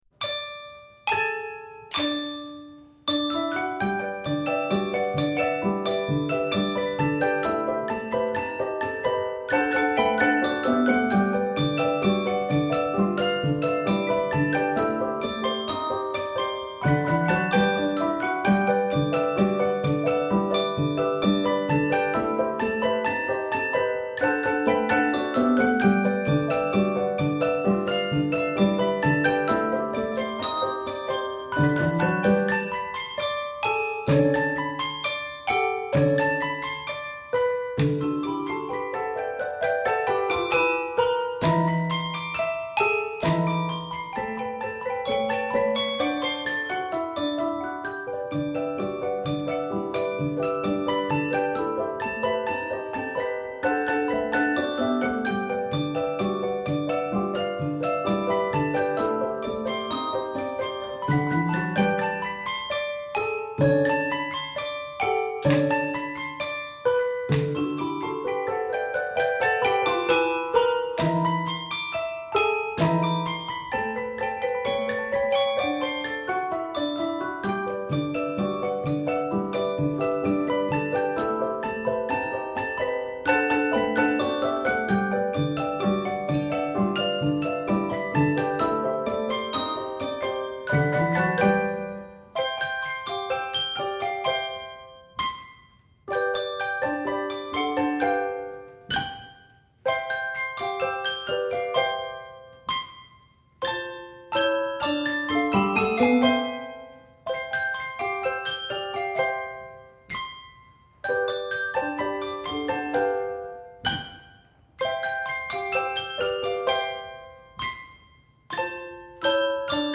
3-5 octave arrangement